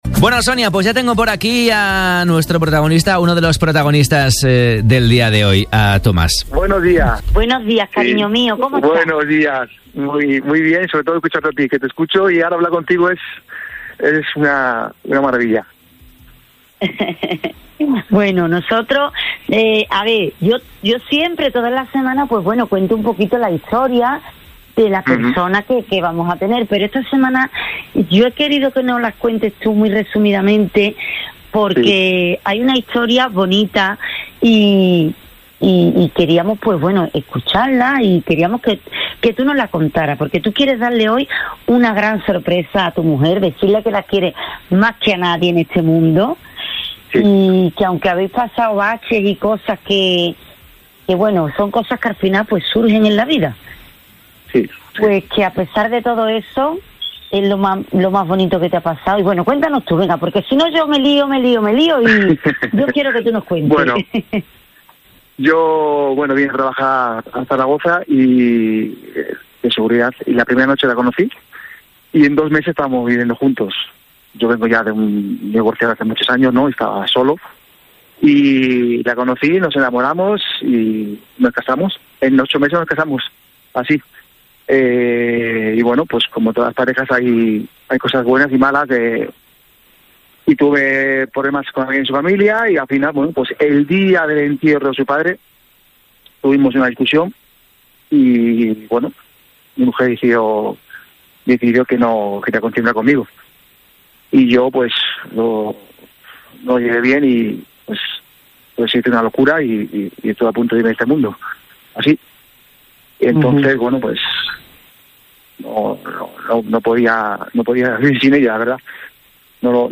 Como todos los miércoles, La Húngara ha visitado Café Olé para sorprender a uno de nuestros pipiolos y, de nuevo, ha conseguido emocionarnos con la historia que ha elegido para esta mañana.